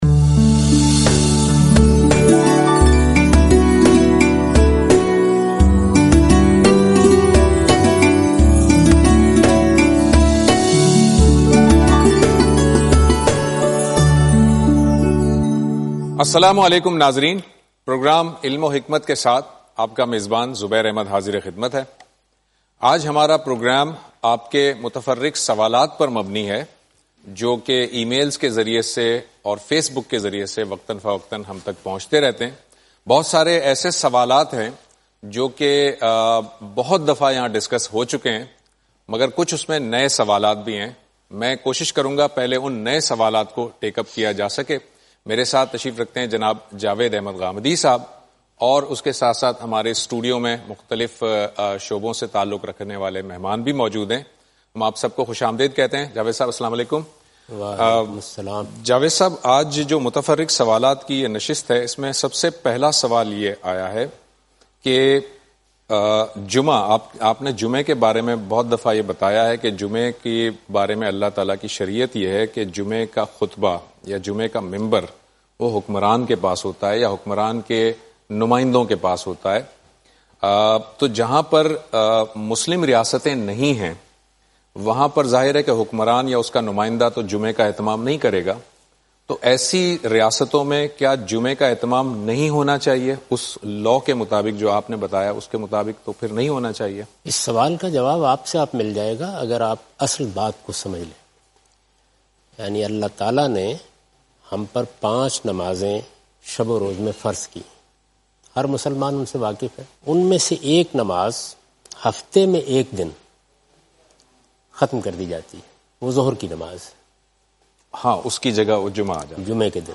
Miscellaneous question and answer